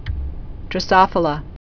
(drə-sŏfə-lə)